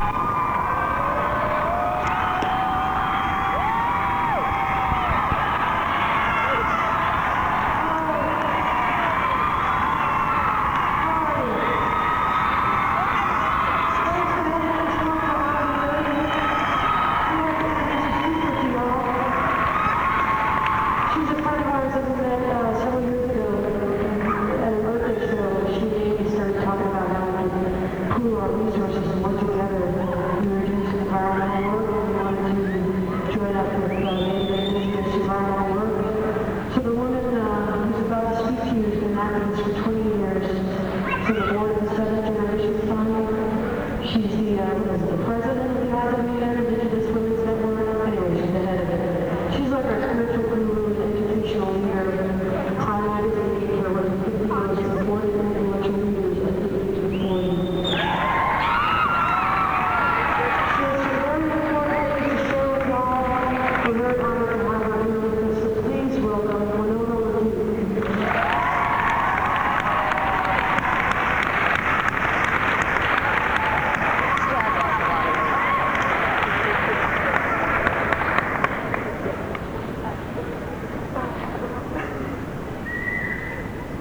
lifeblood: bootlegs: 1997-09-08: shea's auditorium - buffalo, new york (honor the earth)
(band show)